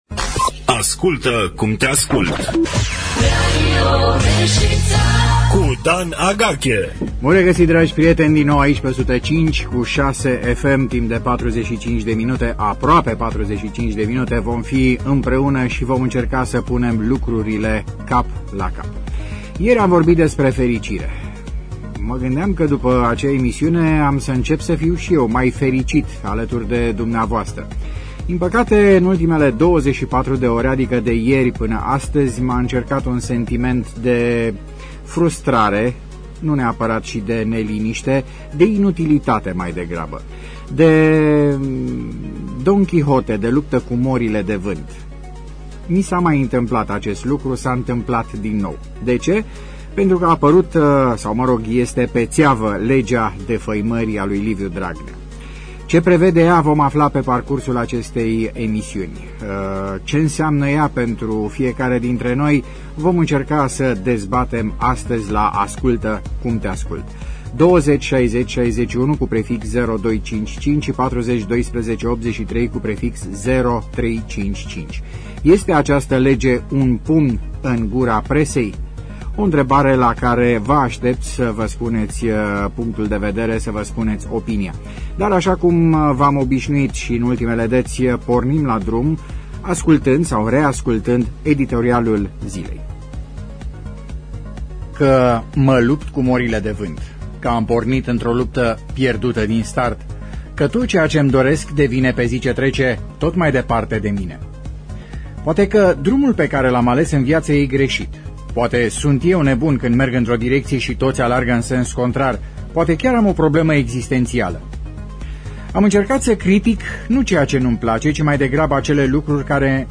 a dialogat cu ascultătorii Radio România Reşiţa pe acest subiect: